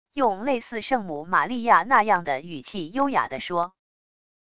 用类似圣母玛利亚那样的语气优雅得说wav音频